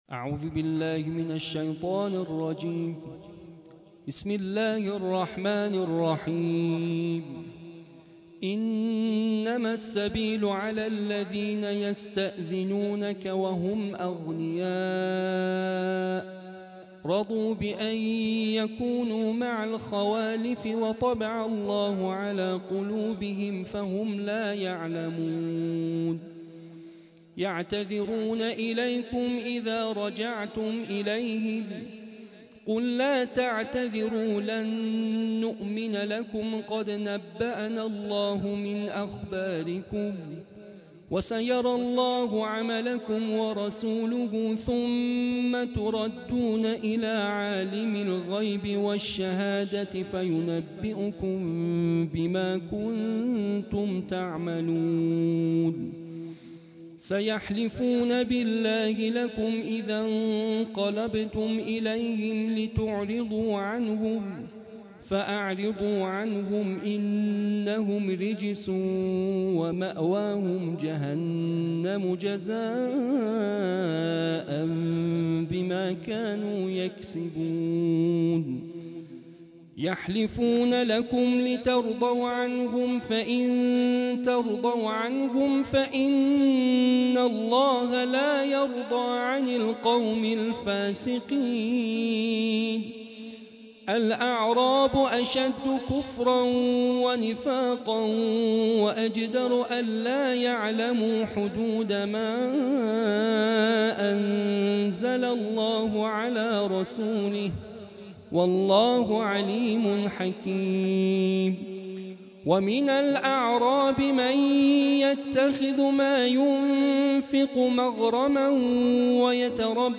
دوازدهمین آئین جزءخوانی قرآن کریم